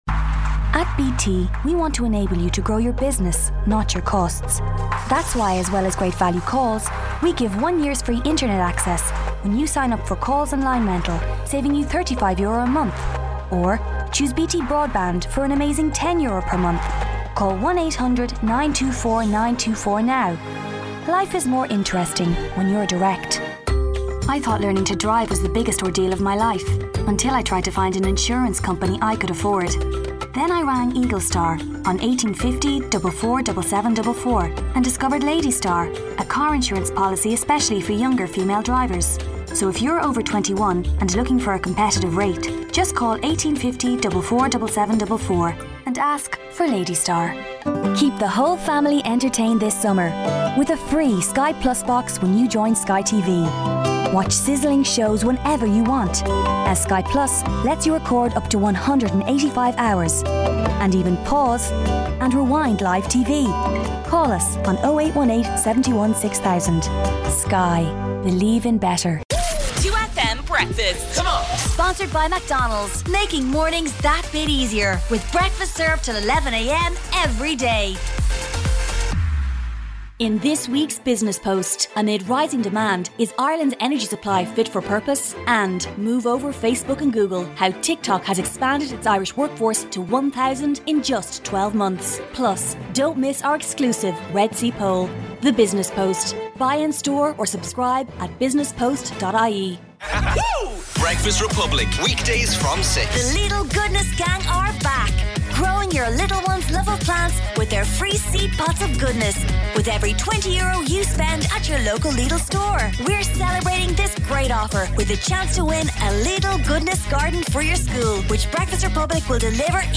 Female
Professional home set-up
20s/30s, 30s/40s
Irish Dublin Neutral, Irish Neutral